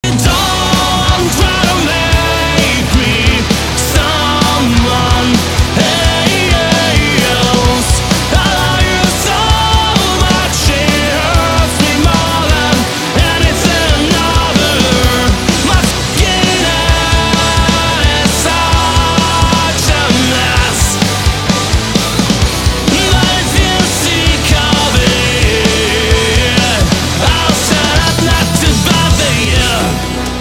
громкие
Alternative Metal
Alternative Rock